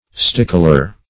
Stickler \Stic"kler\ (st[i^]k"kl[~e]r), n. [See Stickle, v.